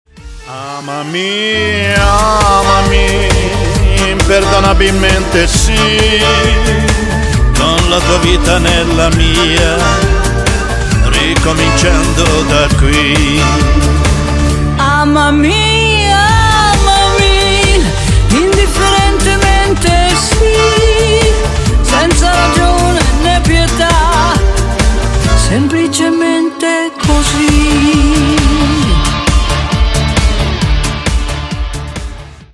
# Поп Рингтоны